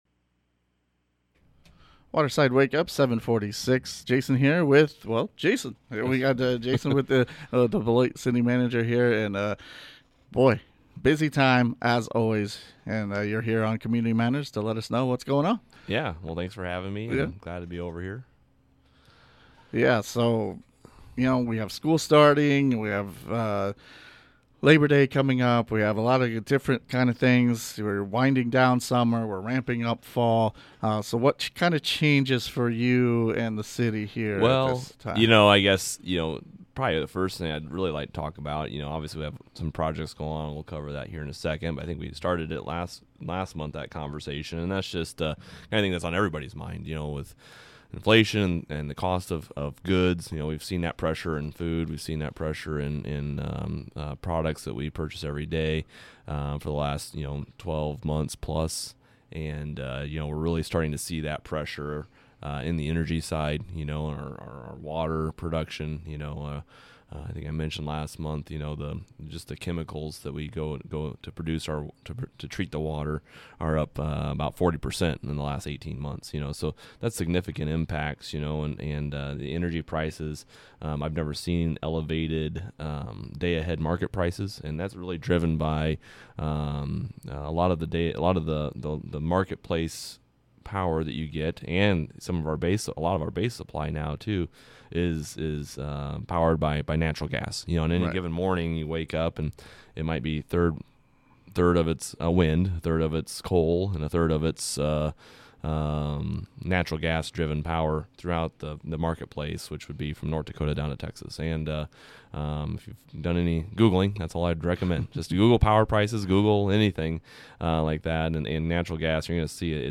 IN STUDIO TO TALK ABOUT ENERGY PRICES AND THE VOLITILITY WITH THOSE PRICES